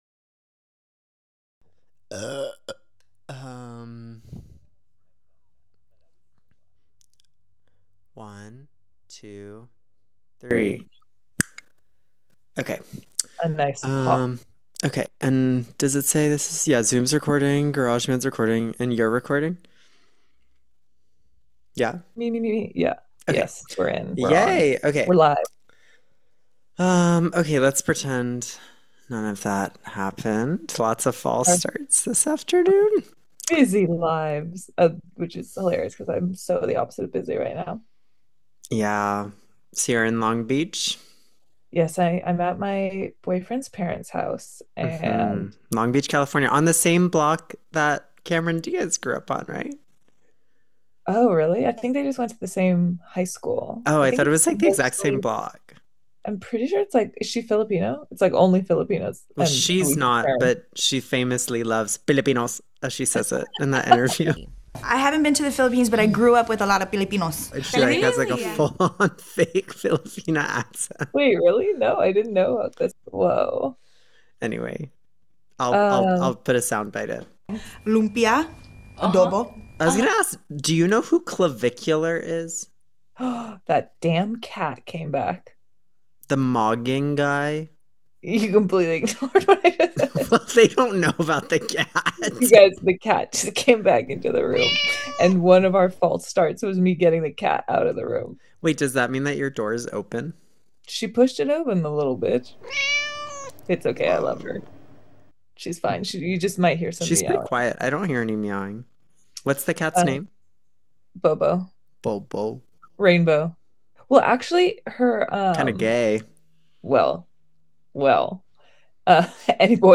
SORRY THE AUDIO SUCKS!
No but really it's our first time recording remotely and we are working out the kinks ;)